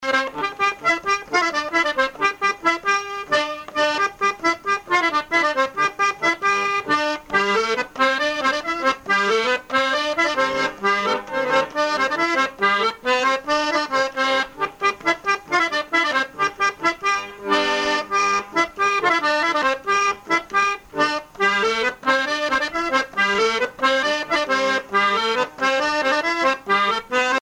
Challans
branle : courante, maraîchine
musique à danser à l'accordéon diatonique
Pièce musicale inédite